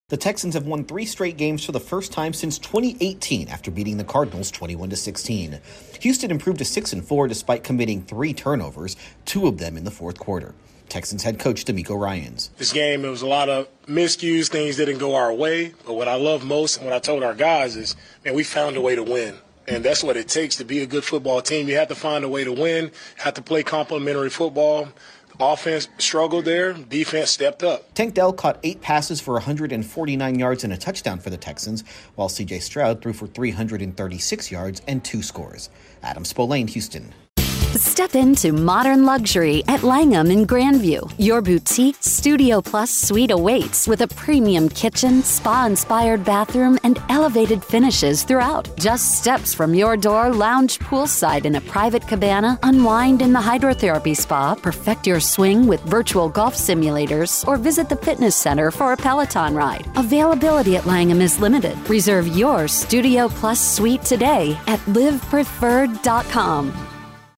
The Texans stretch their winning streak heading into a big showdown with the Jaguars. Correspondent